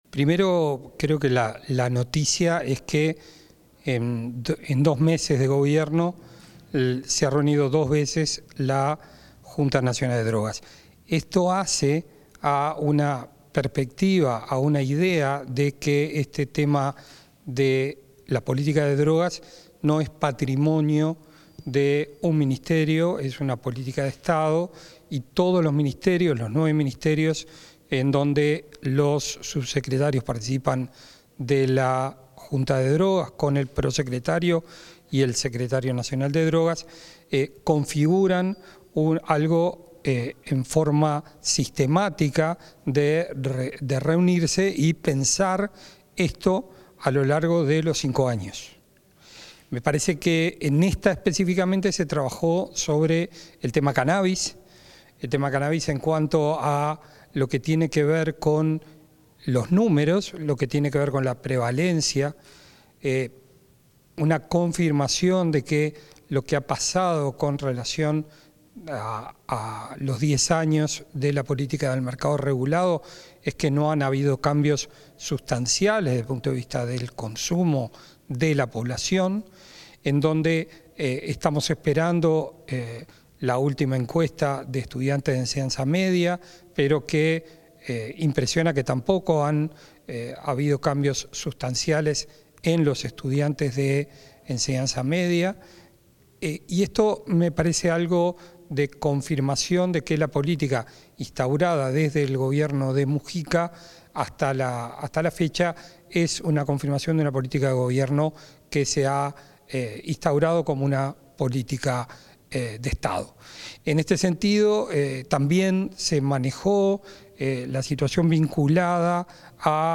Entrevista al titular de la Secretaría Nacional de Drogas, Gabriel Rossi
Luego del encuentro, el titular de la Secretaría Nacional de Drogas, Gabriel Rossi, efectuó declaraciones.